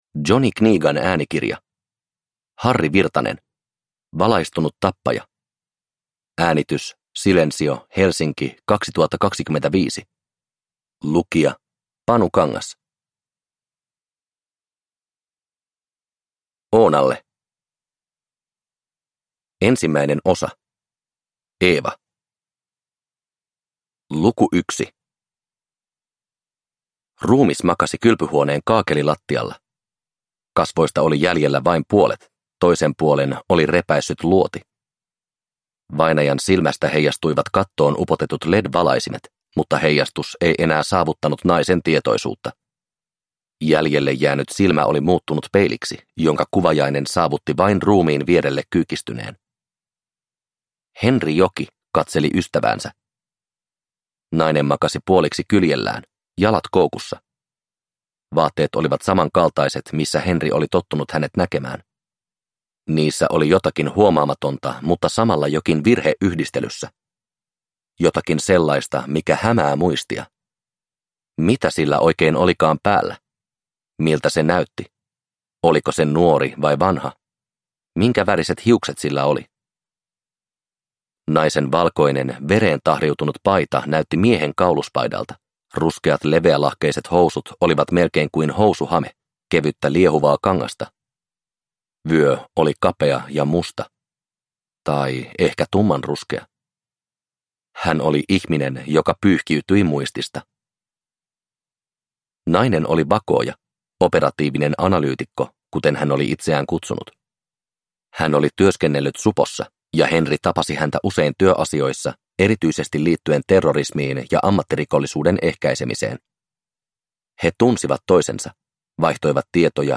Valaistunut tappaja – Ljudbok